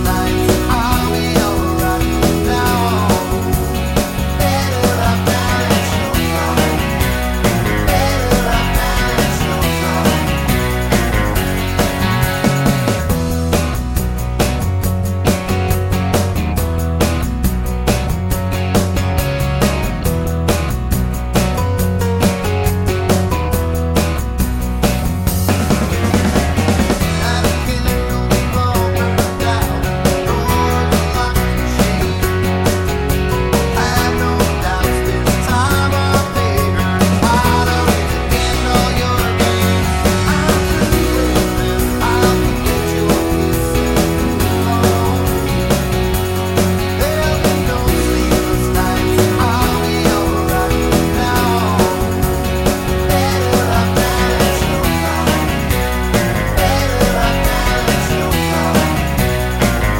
for male female duet Pop